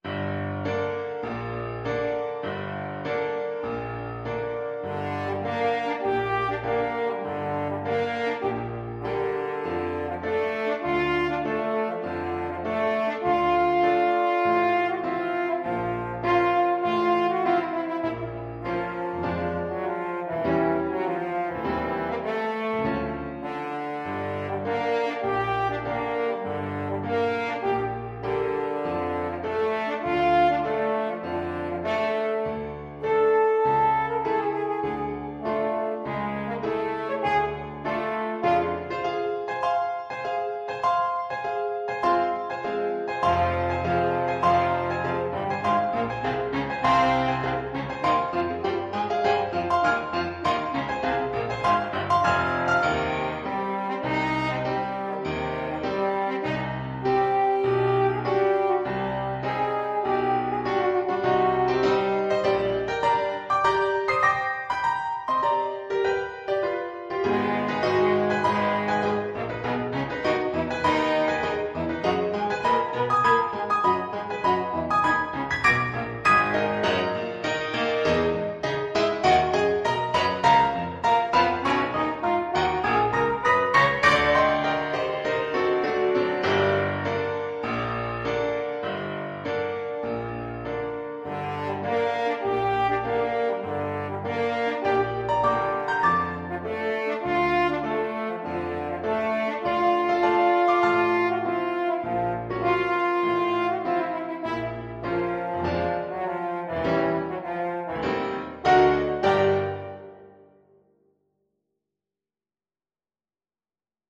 4/4 (View more 4/4 Music)
Classical (View more Classical French Horn Music)